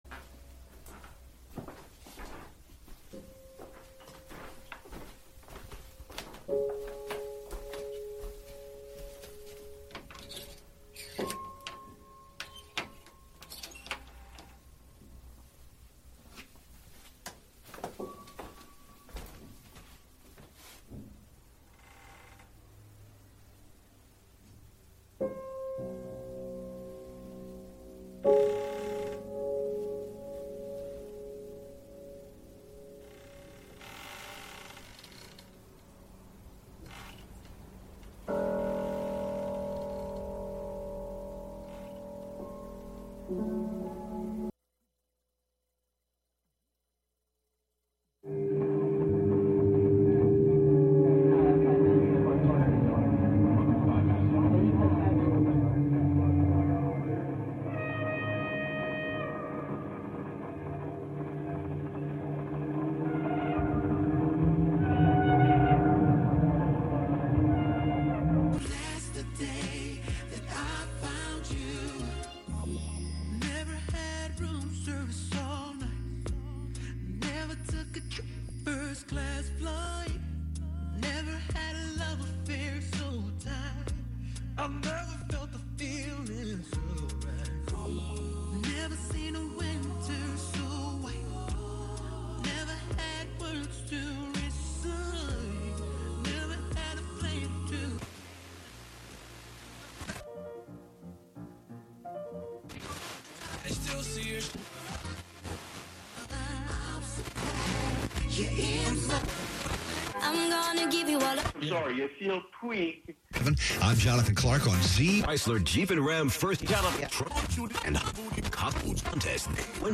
Live from Brooklyn, NY
Radio Wonderland abstracts live FM radio with laptop, electrified shoes hit with sticks, and a computer-hacked steering wheel (from a Buick 6). Radio Wonderland's software creations include The Reshuffler (deploy slices of radio on a rhythmic grid making instant techno 90% of the time) the Re-Esser (extract the sibilance, play those S's, T's and K's like a drum machine,) and Anything Kick (morph slowed-down radio into a bass drum to shake the dance floor).